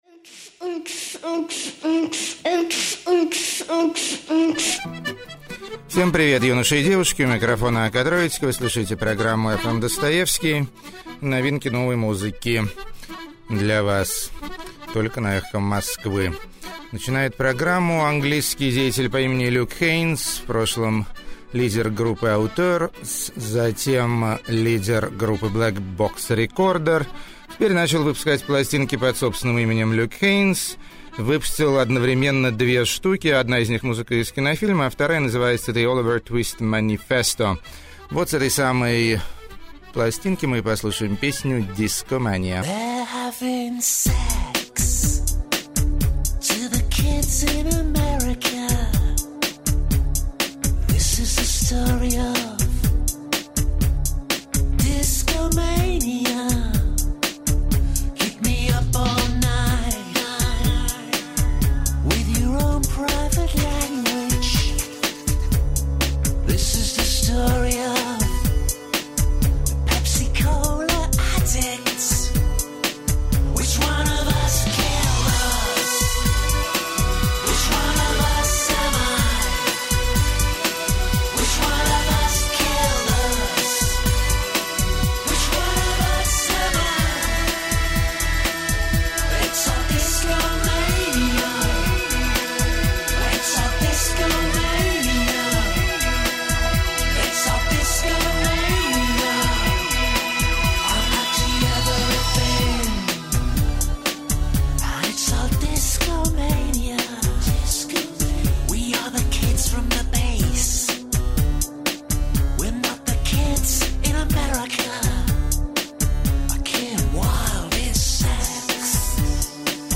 Довольно Безумный И Обаятельный Lo-fi.
Художественный Свист.] 4.
Чёрный Шансон Из Ночного Зоопарка.
Психо-прог.
Органольный Ретро-футуризм.
Сумеречный Фольклор И Маниакальные Колыбельные.
Музыка Покоя И Безволия.